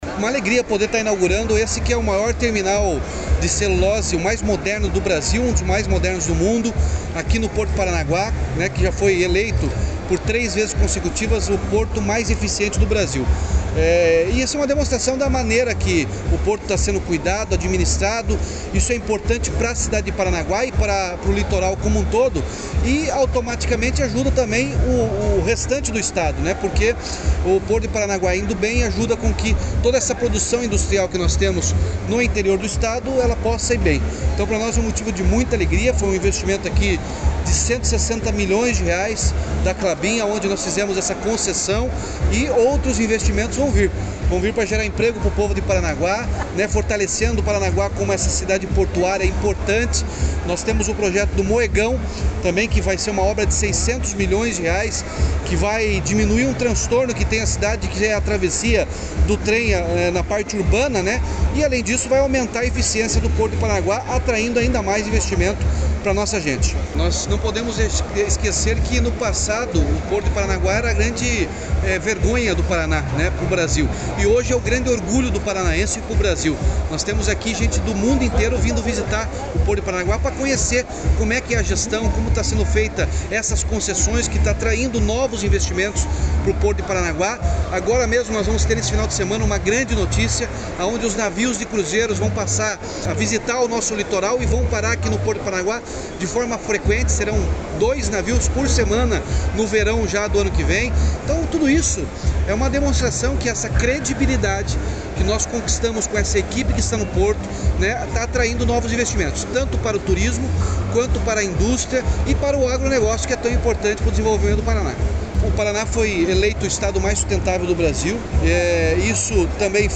Sonora do governador Ratinho Junior sobre a inauguração do novo terminal da Klabin no Porto de Paranaguá